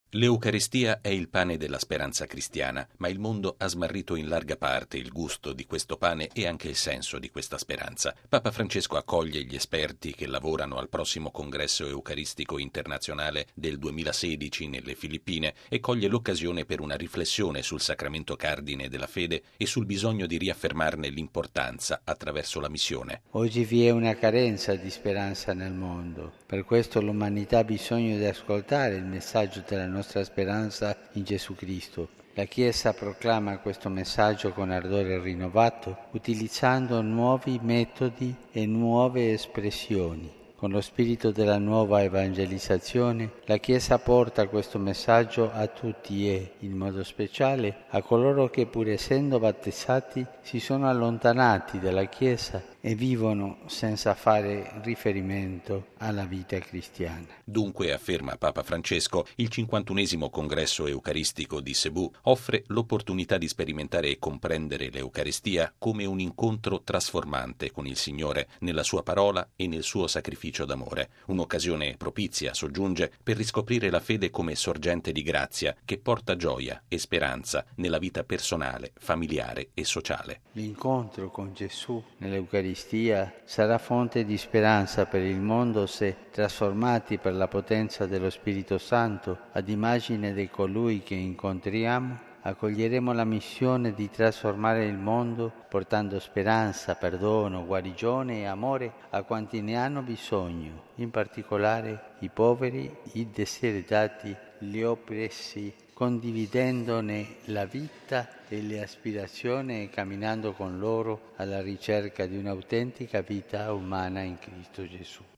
Al termine di una sessione di lavori, Papa Francesco ha ricevuto in udienza il gruppo di delegati e ha ribadito loro: la speranza per il mondo si fonda sull’incontro con Gesù Eucaristia. Il servizio